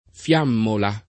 fiammola [ f L# mmola ]